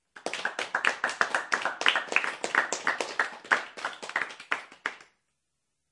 掌声 " 掌声 5/6人 3人
描述：一个小团体在鼓掌。
标签： 拍手 欢呼 速度快 观众 鼓掌 人群 掌声
声道立体声